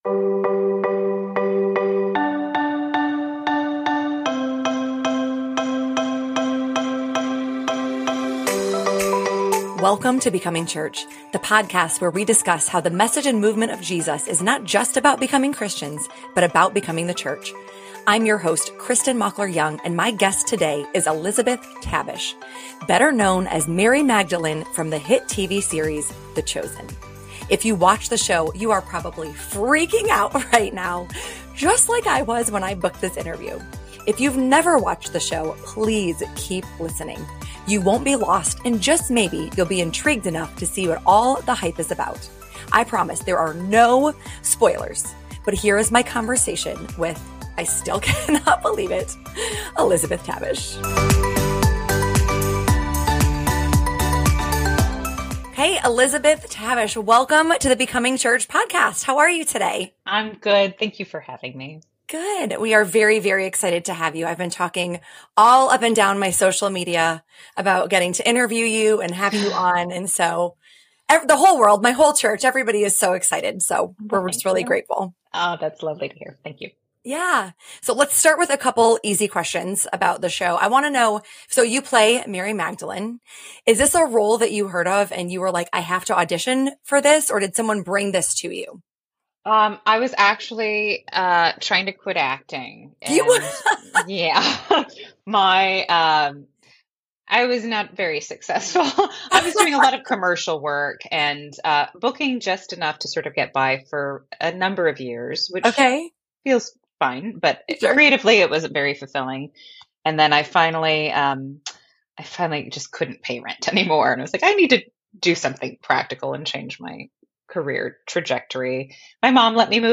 I had to answer that question when I sat down with Elizabeth Tabish who plays Mary Magdalene on The Chosen.